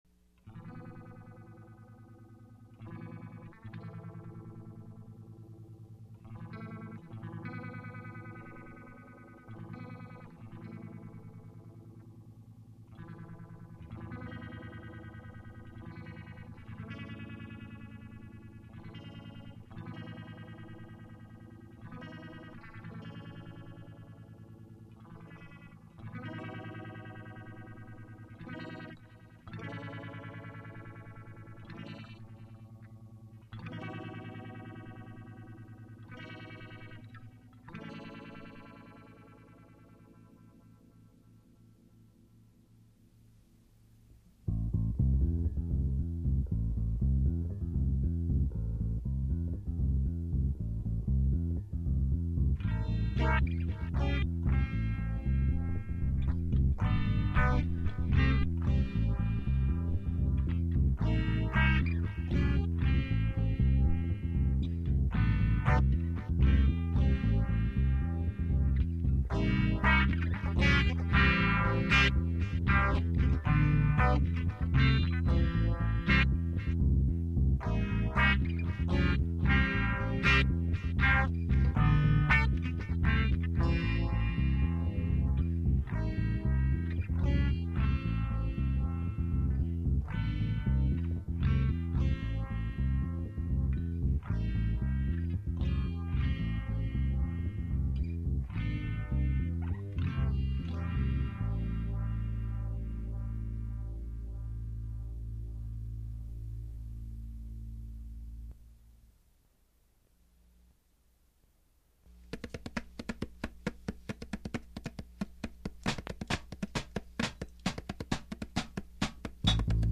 Guitar, Vocals, Percussion
Bass, Vocals, Percussion